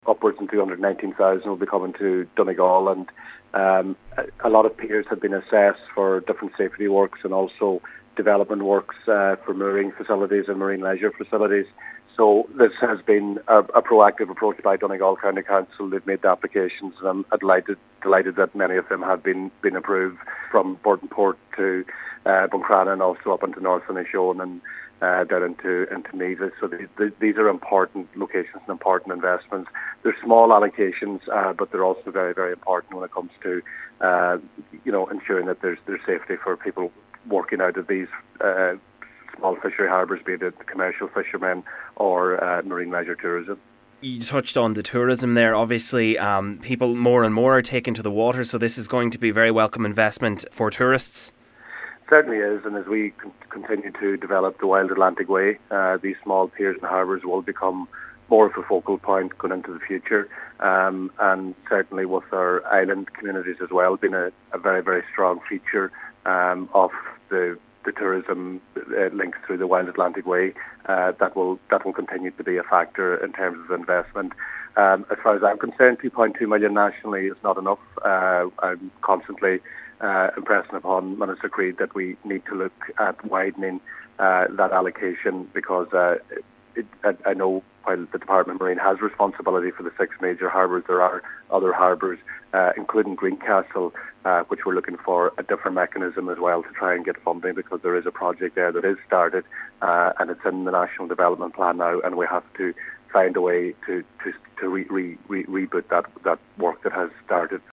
Donegal TD Joe McHugh has welcomed the announcement, but says that the overall investment nationally is not enough: